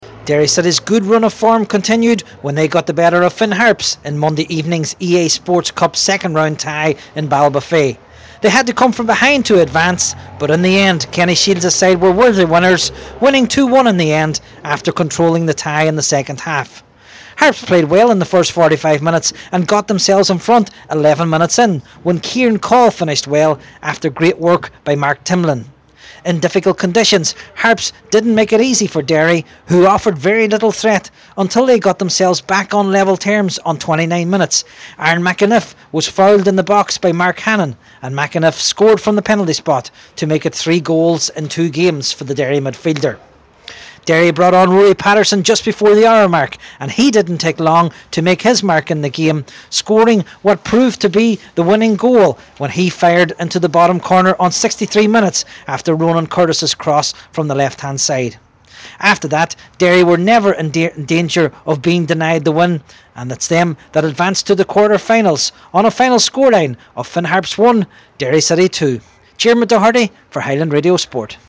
full-time report…